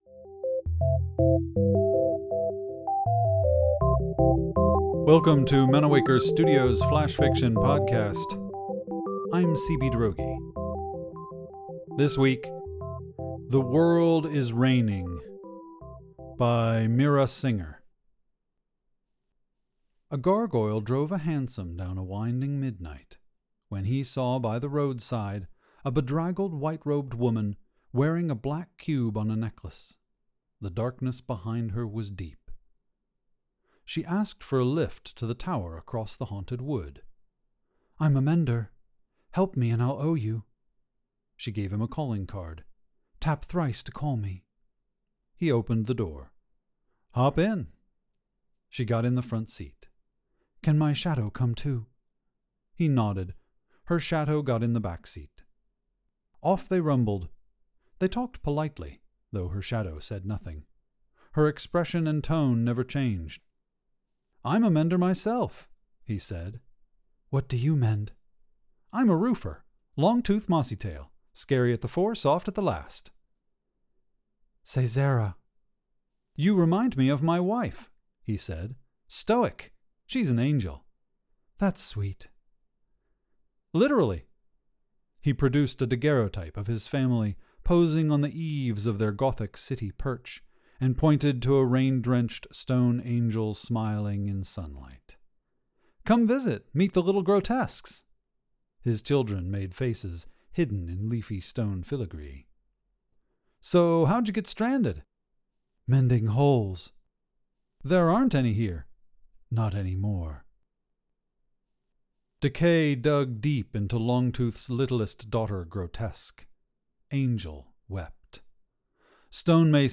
Manawaker Studio's Flash Fiction Podcast